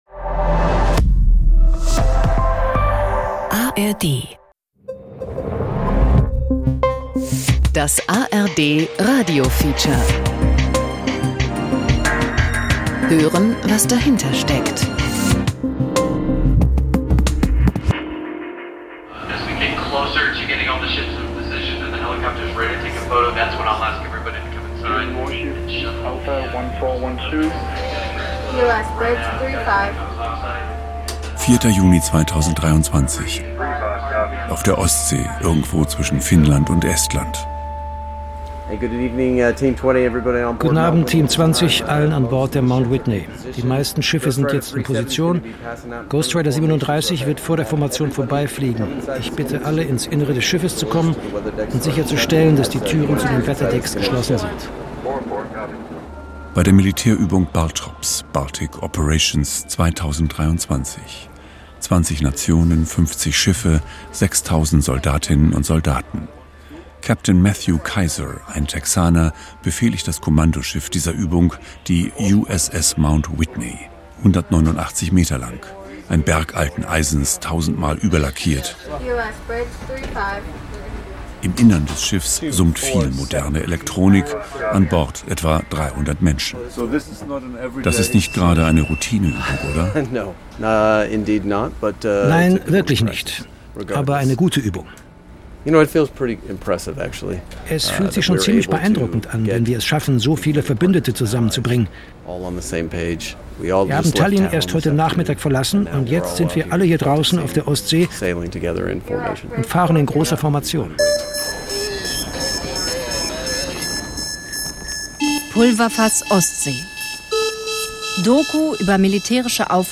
das ARD radiofeature "Pulverfass Ostsee"